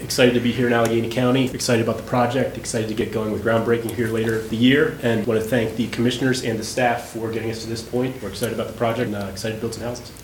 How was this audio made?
During last night’s Allegany County Commissioner meeting, the commissioners approved the development agreement with D.R. Horton to build 65 homes in the old Allegany High School neighborhood.